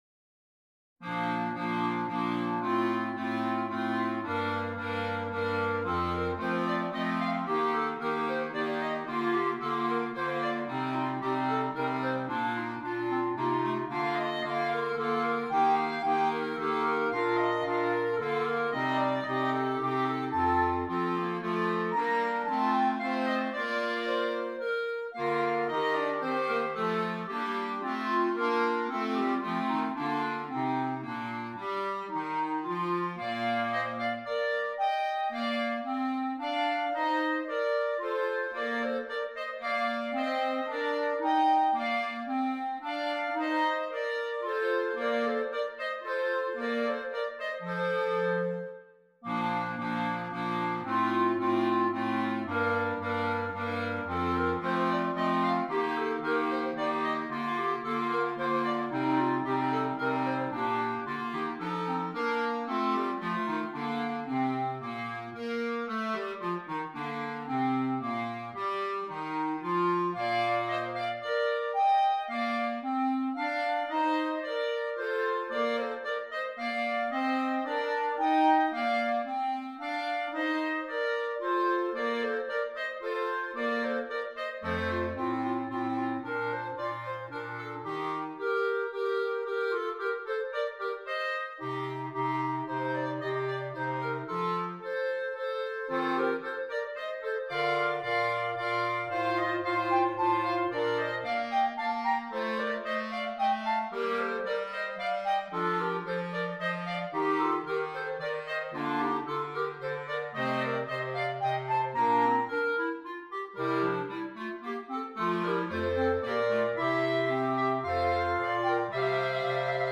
4 Clarinets, Bass Clarinet